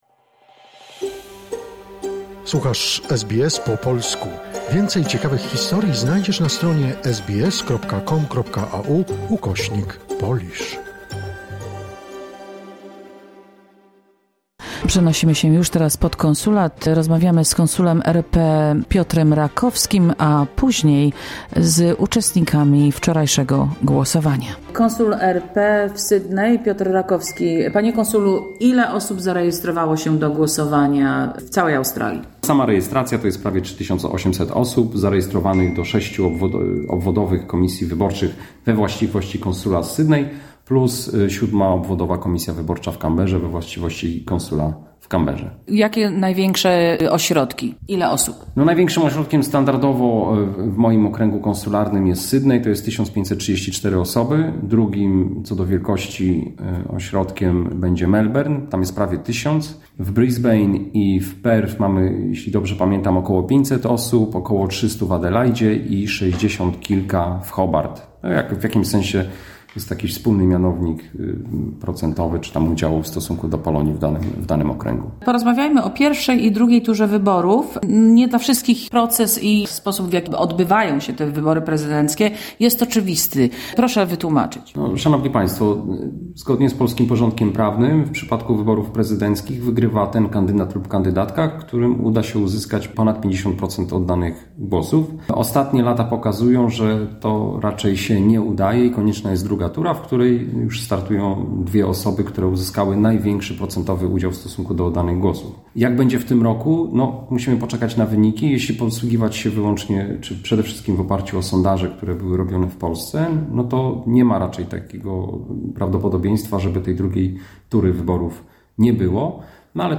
Liczbę zarejestrowanych i zasady głosowania w I i II turze wyborów przekazał Konsul Generalny RP Piotr Rakowski. Wypowiedzi głosujących w konsulacie w Sydney zebrała redakcja SBS Polish.